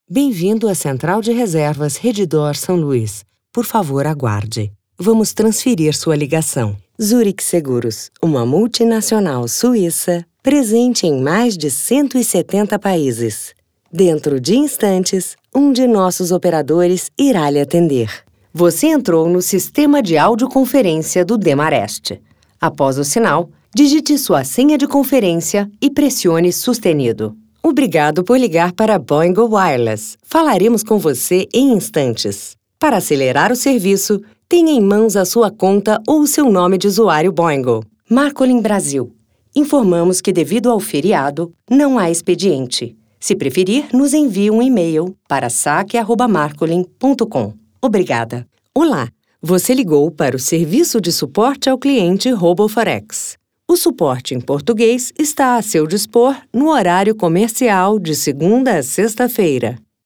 Feminino
Espera telefônica